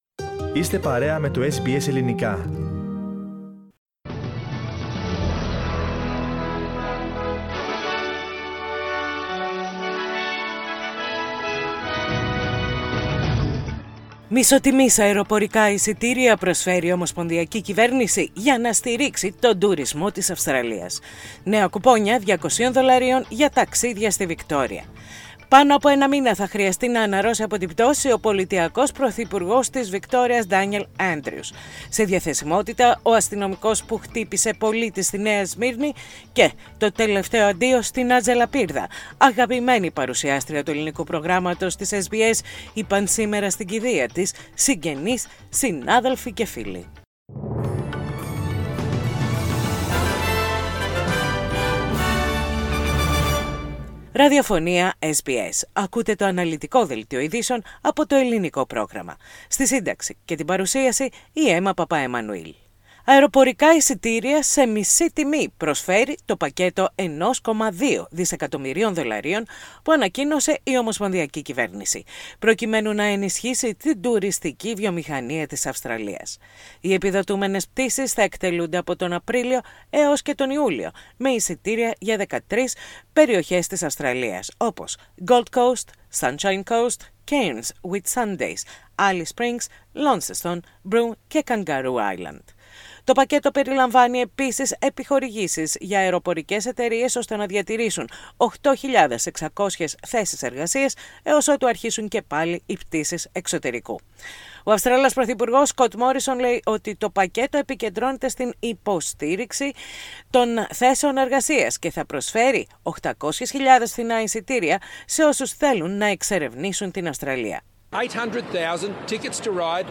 Ειδήσεις στα Ελληνικά - 11.3.21